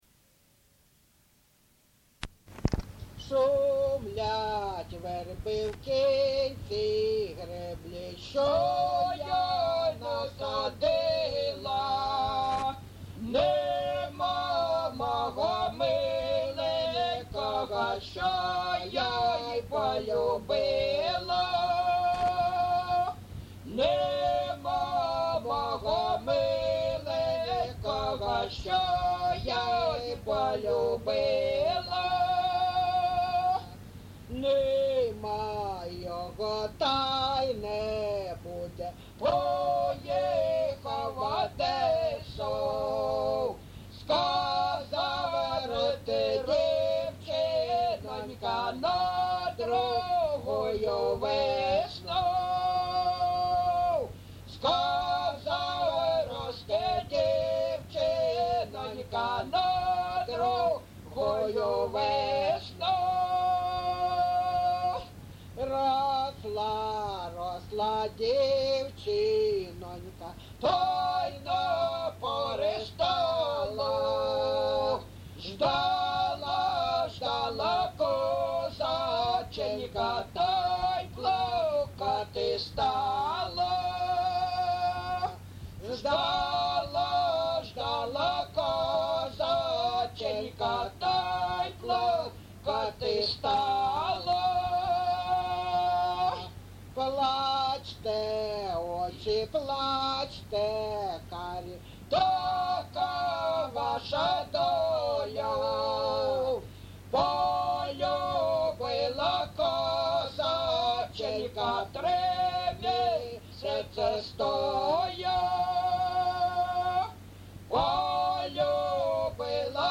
Місце записус. Григорівка, Артемівський (Бахмутський) район, Донецька обл., Україна, Слобожанщина